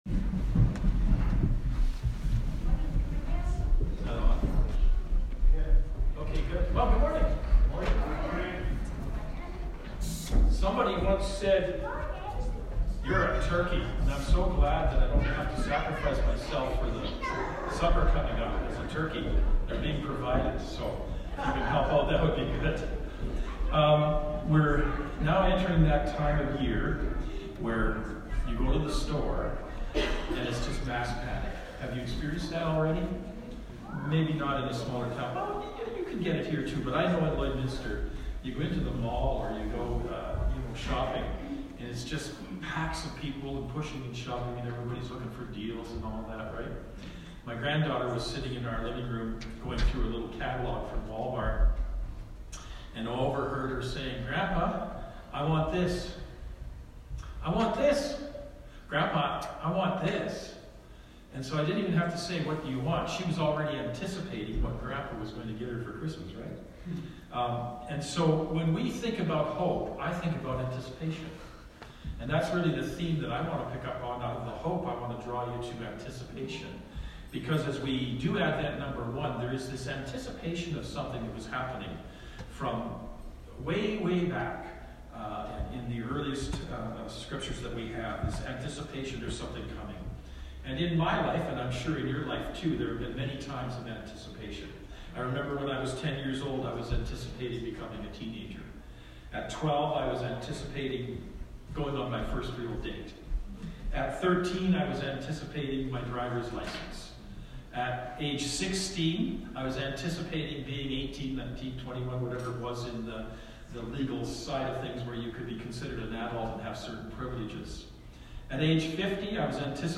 Sermons | Provost Community Church